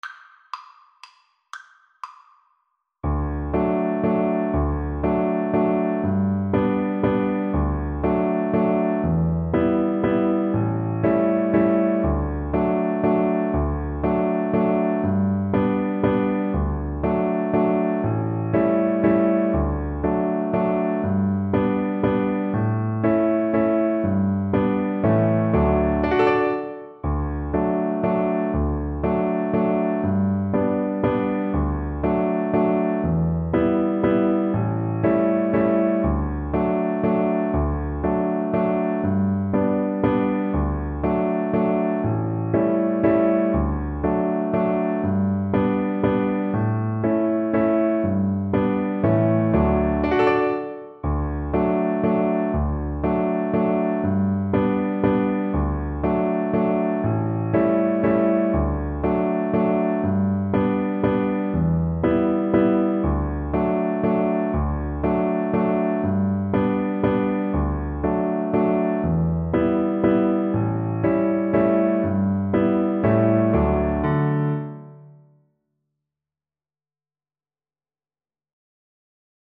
Cello
3/4 (View more 3/4 Music)
Allegro (View more music marked Allegro)
D major (Sounding Pitch) (View more D major Music for Cello )
Traditional (View more Traditional Cello Music)